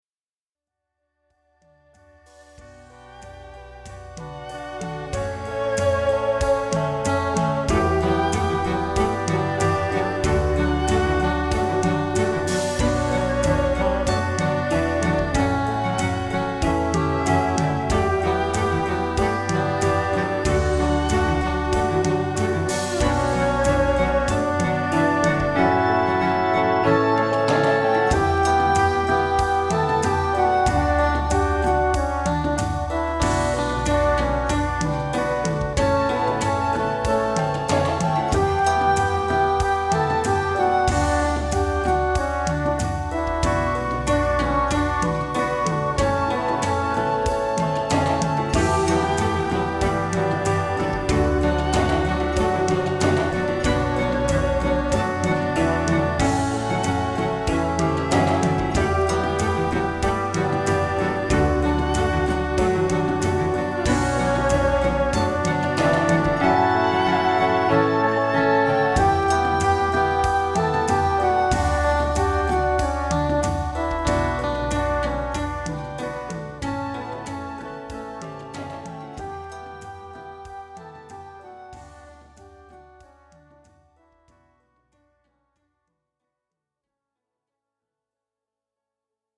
To me it sounds a bit like plastic, and i am wondering if my sound card can be the issue?
I want to state that the songs are still under proccess but you should get an idea of the sound.
What I hear is everything competing for the same sonic area - some EQing on certain tracks and judicious panning - it seems like many of the tracks are panned wide.